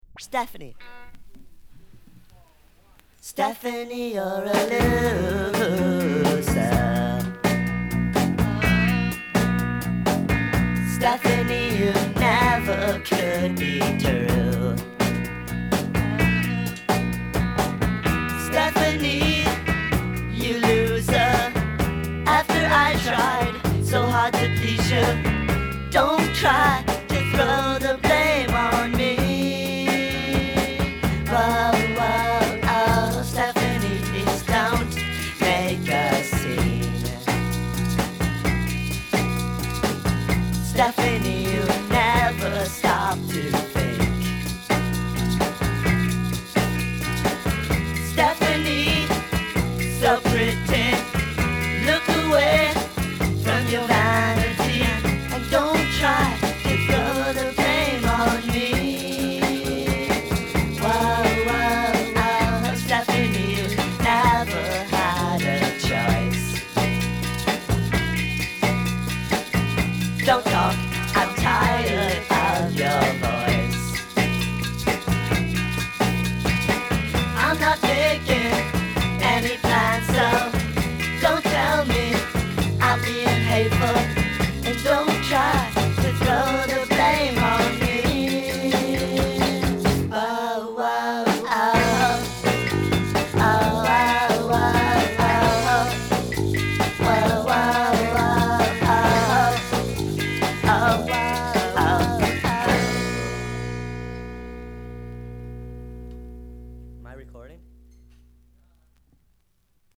ハモンドB3を前面に出した素朴なポップ・バラード。